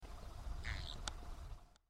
Chilean Flamingo (Phoenicopterus chilensis)
Life Stage: Adult
Location or protected area: Valle Inferior del Río Chubut (VIRCH)
Condition: Wild
Certainty: Observed, Recorded vocal
flamenco.mp3